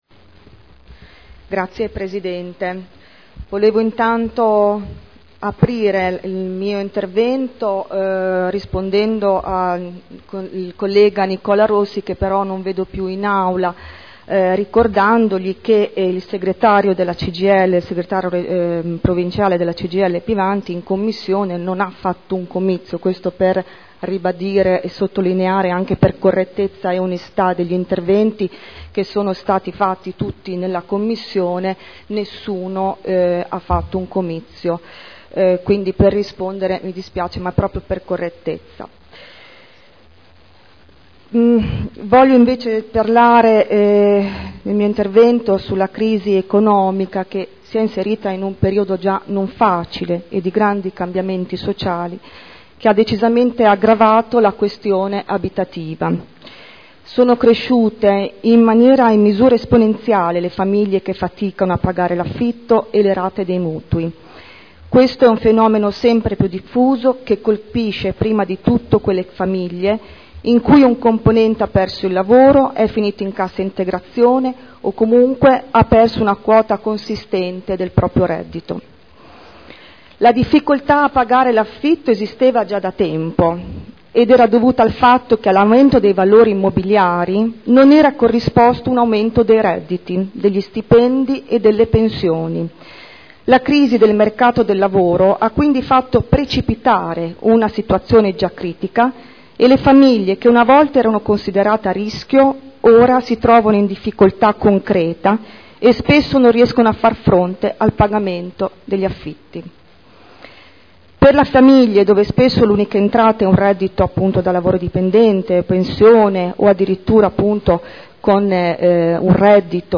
Seduta del 28/06/2010